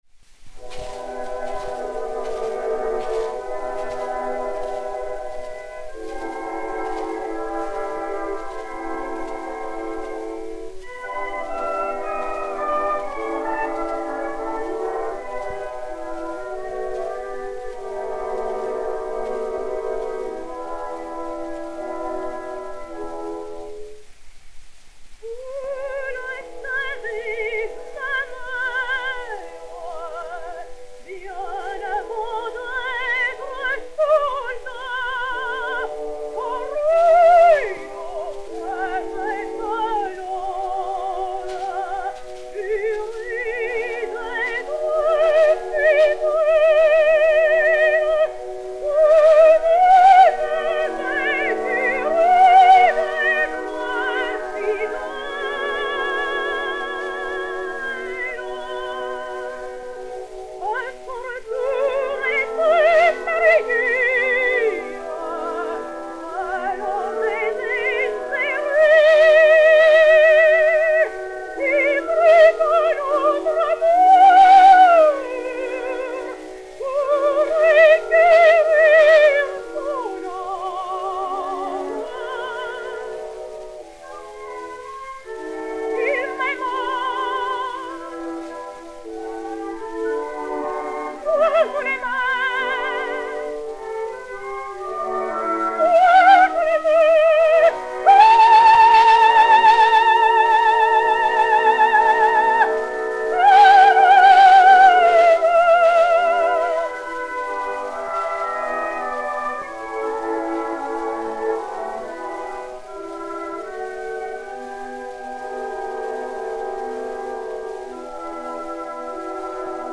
Suzanne Brohly (Leonor) et Orchestre
Disque Pour Gramophone 033102, mat. 0886v, enr. à Paris le 05 février 1909